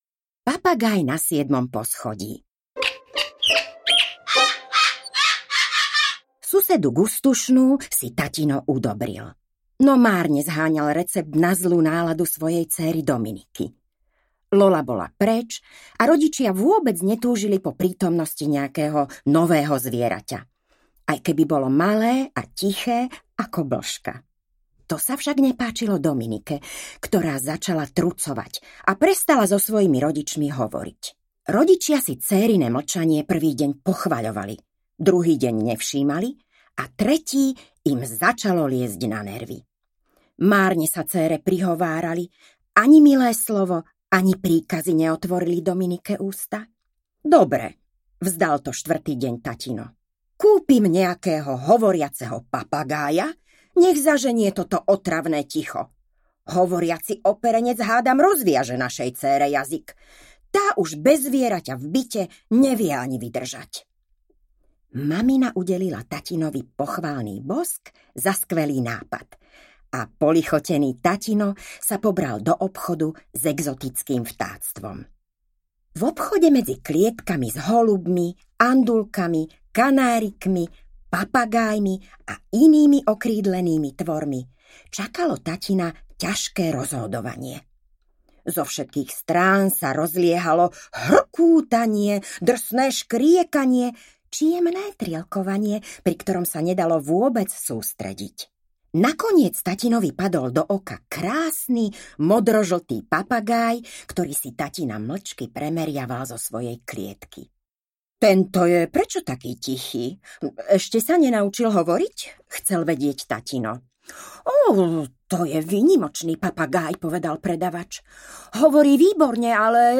Zverinec na siedmom poschodí audiokniha
Ukázka z knihy